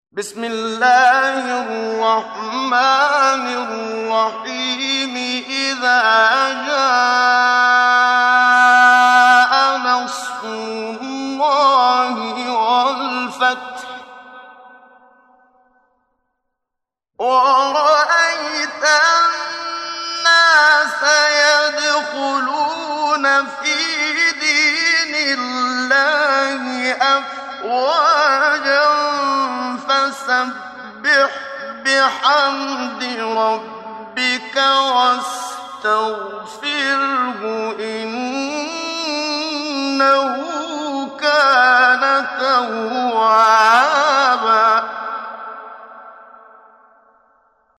محمد صديق المنشاوي – تجويد – الصفحة 9 – دعاة خير